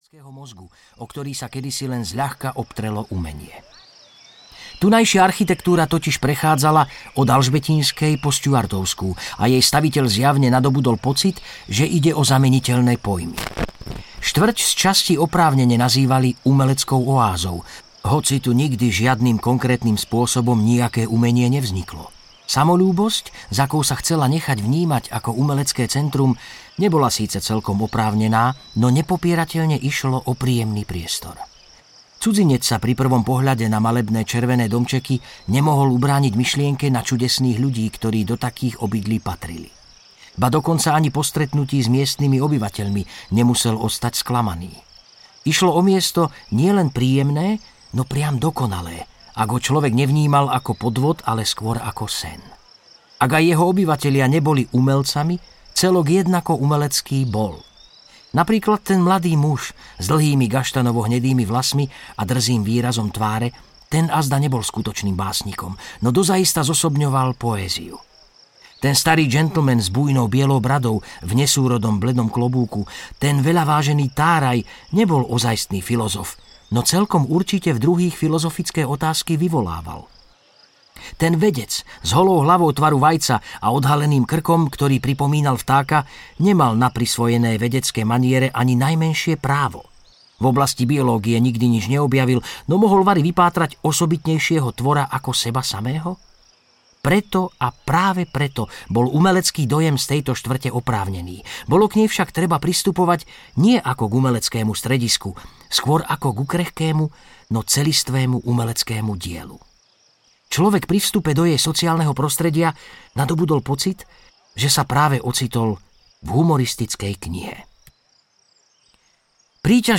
Muž, ktorý bol štvrtok audiokniha
Ukázka z knihy